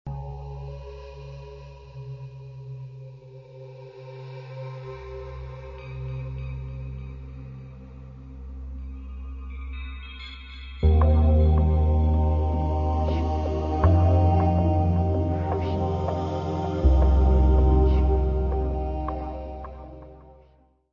: stereo; 12 cm
Área:  Pop / Rock